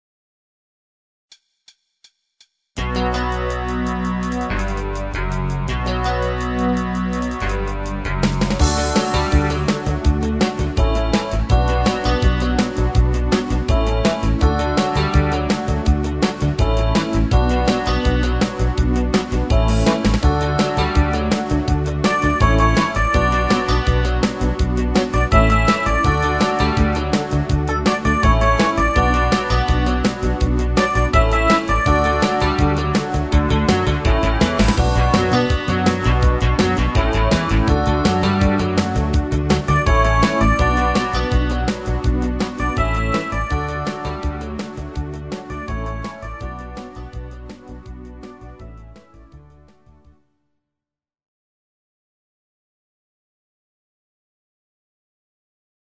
Genre(s): Deutschpop  NDW  |  Rhythmus-Style: Fastbeat